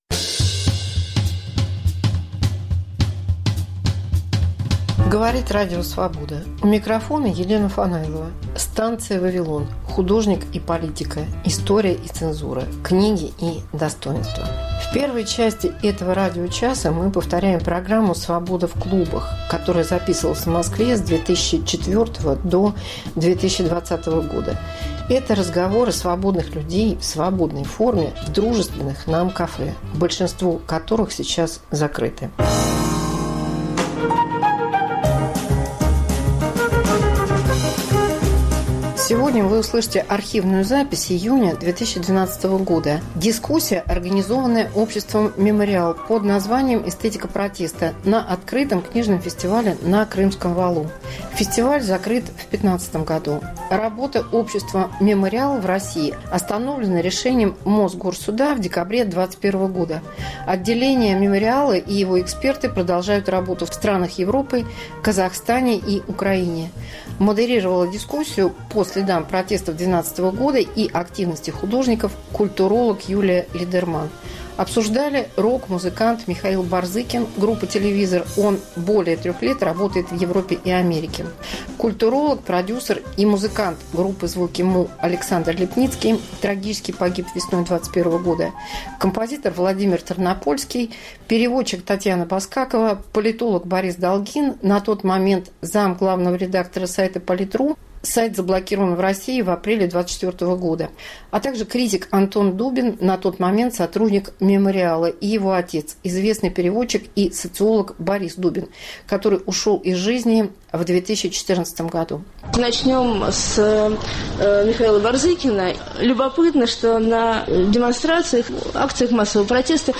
1. Уличные протесты 2012 года, архив.